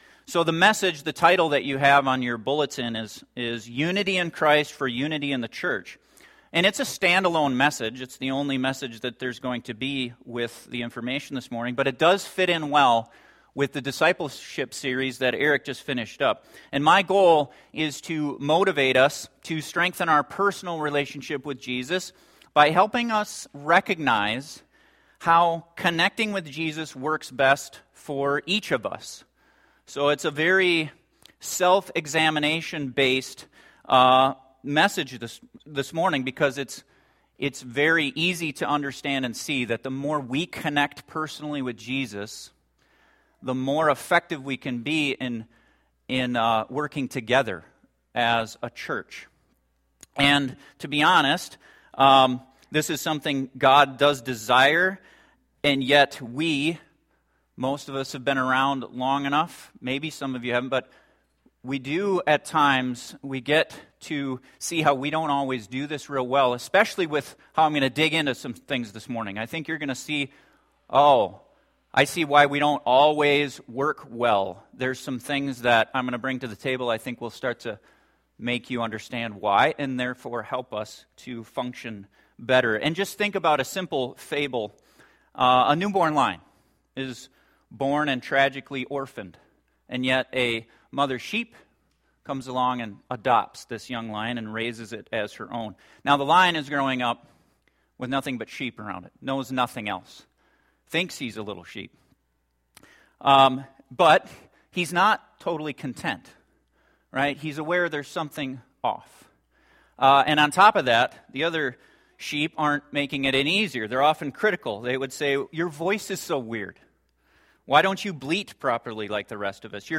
This sermon will help explain why that’s the case so you can understand yourself and others better and why people worship the way they do.